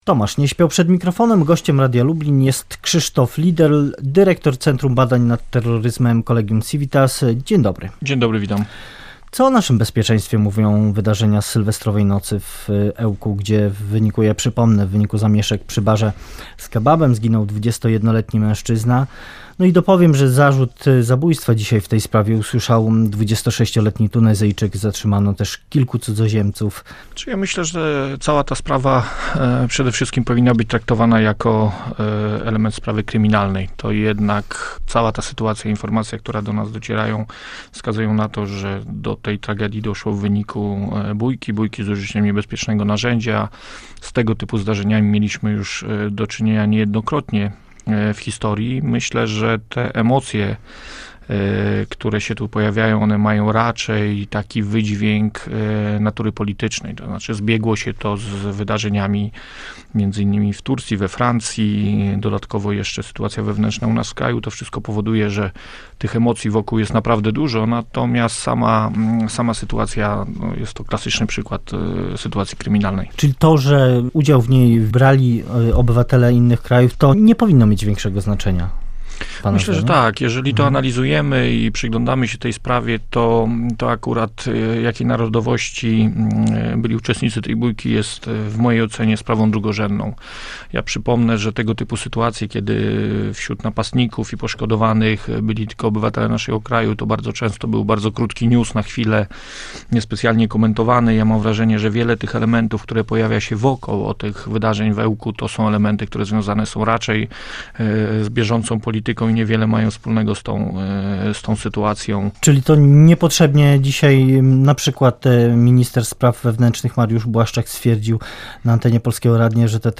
Z gościem rozmawiał